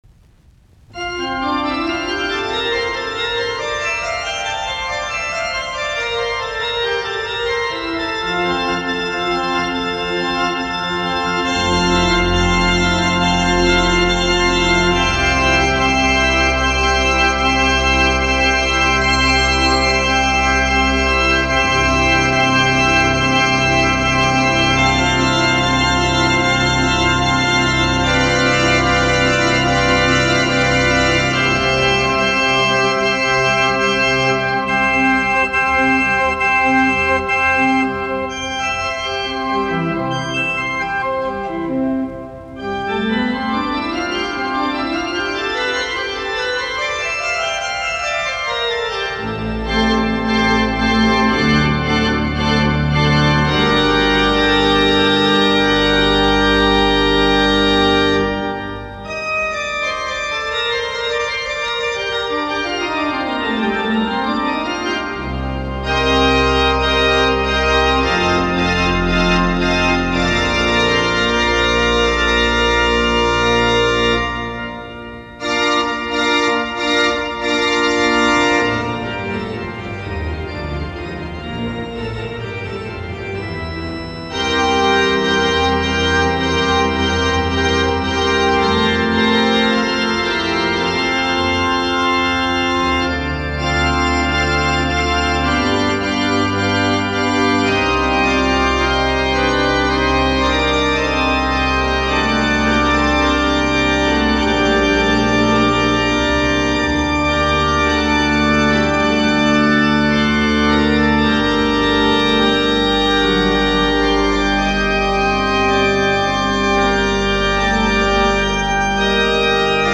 Soitinnus: Urut.